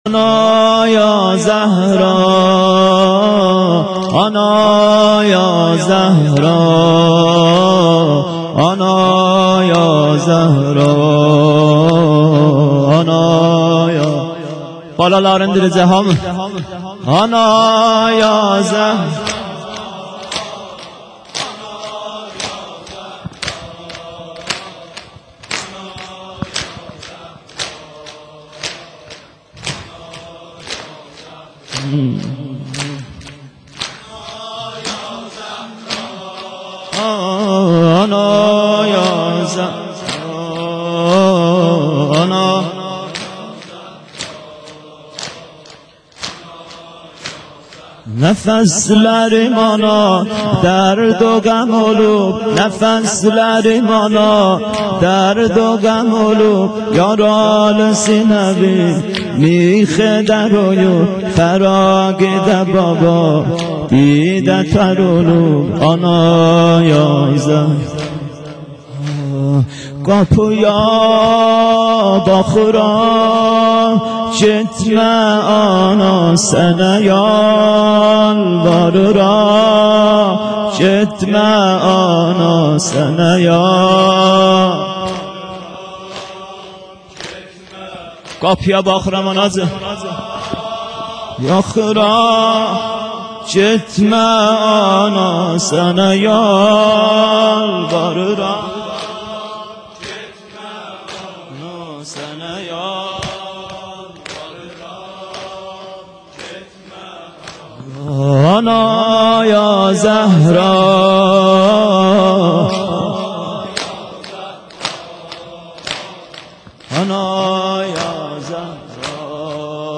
فاطمیه 95- شب شهادت- بخش سوم سینه زنی
فاطمیه 95- شب شهادت- آنا یا زهرا آنا یا زهرا